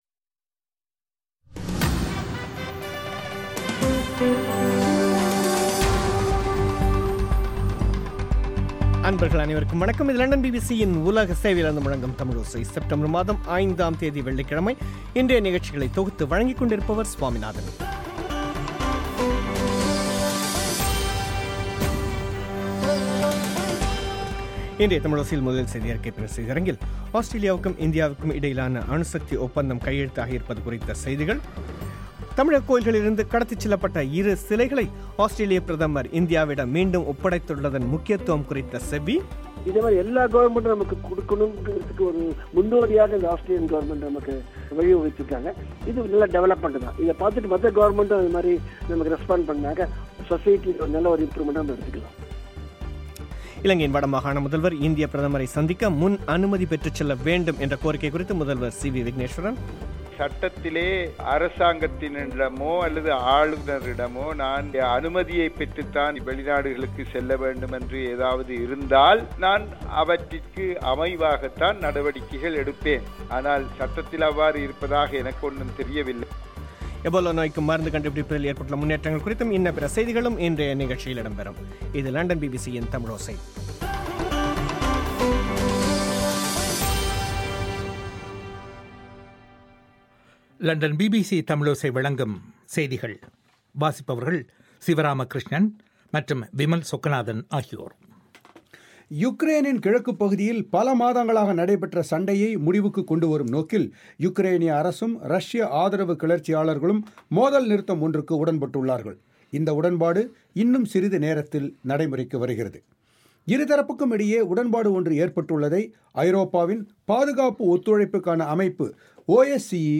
தமிழகக் கோயில்களில் இருந்து கடத்திச் செல்லப்பட்ட இரு சிலைகளை ஆஸ்திரேலியப் பிரதமர் ஒப்படைத்துள்ளதன் முக்கியத்துவம் குறித்த செவ்வி